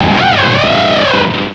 sovereignx/sound/direct_sound_samples/cries/luxray.aif at master